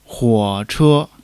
huo3-che1.mp3